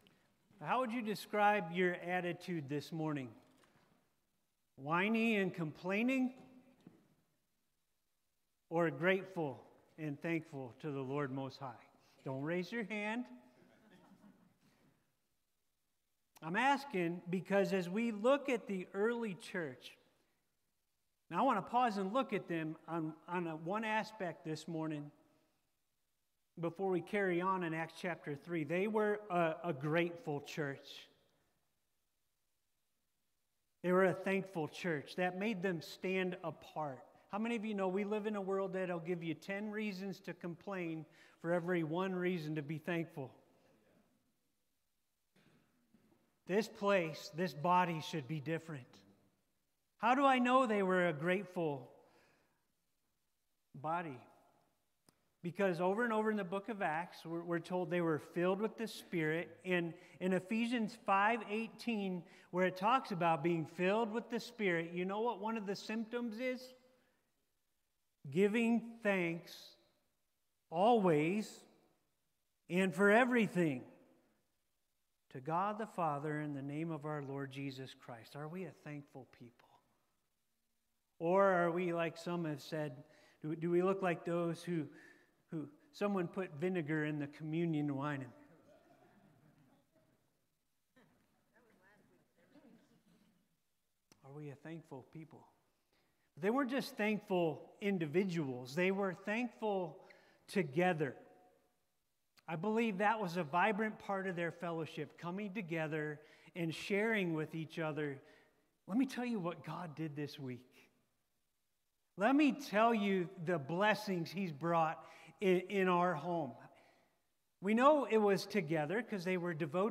In this service we visit a Psalm of thankfulness and explore how to be thankful today. You’ll also enjoy some testimonies.